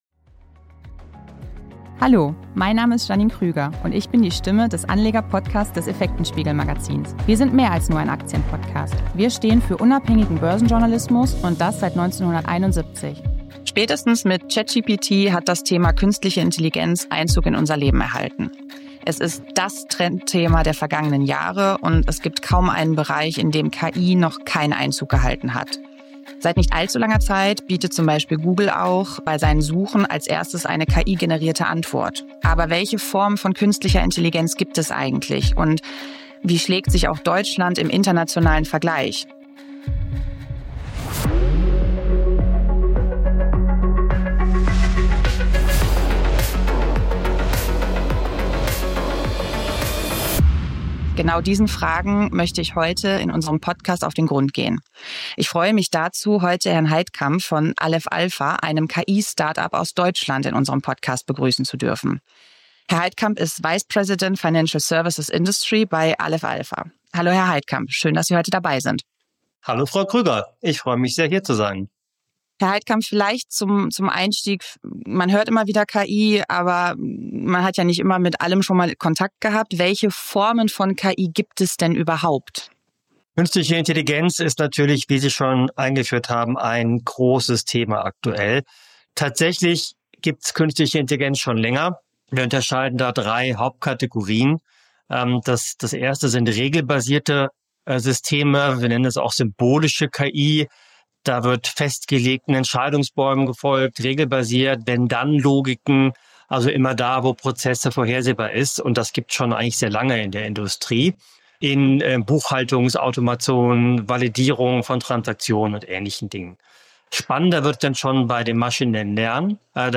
Podcast-Interview